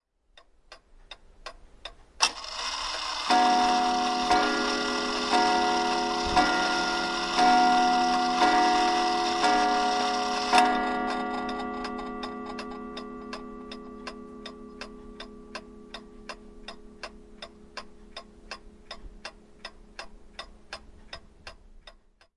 挂钟报时和滴答声
描述：滴答作响的挂钟响9次，10次和11次。包括滴答声。时钟很旧，所以也许铃声不是那么完美，而不是新铃。
标签： 挂钟 蜱滴答 壁虱 时间 金属 时钟 古董
声道立体声